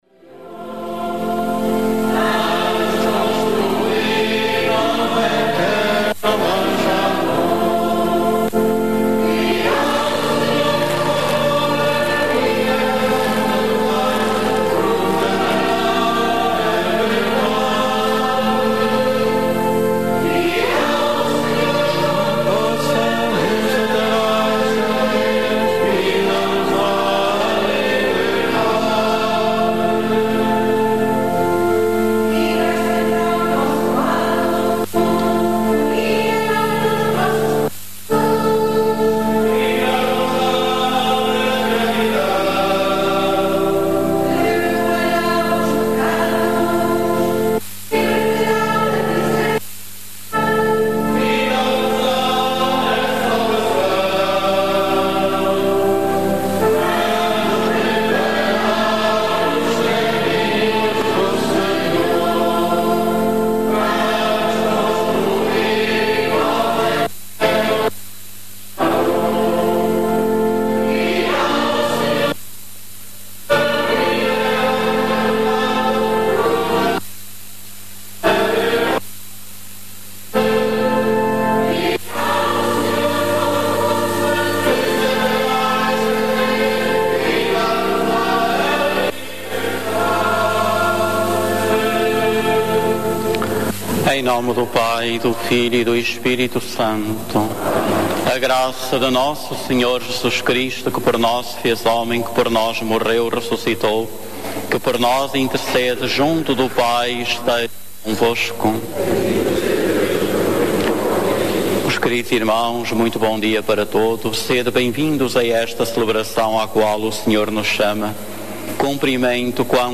A Rádio Clube de Lamego transmite todos os Domingos a Eucaristia, em direto, desde o Santuário de Nossa Senhora dos Remédios em Lamego a partir das 10 horas.